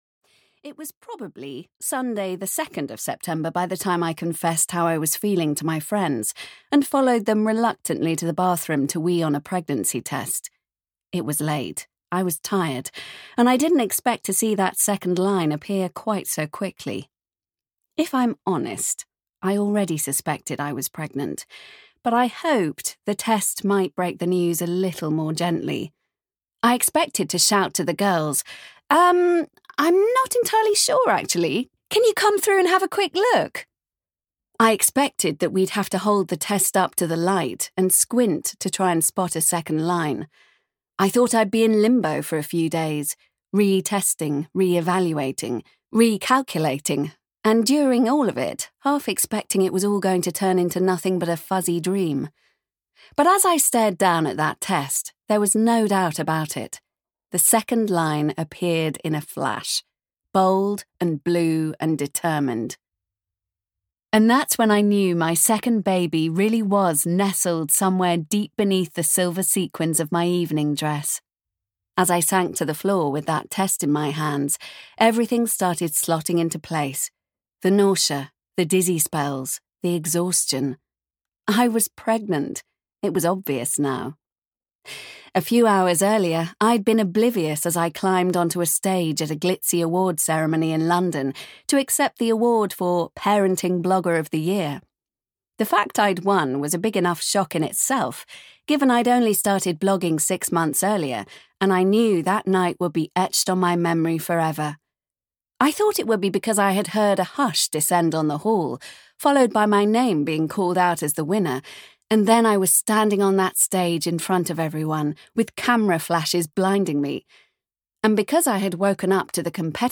Audiobook Mum's Big Break written by Louise Emma Clarke.
Ukázka z knihy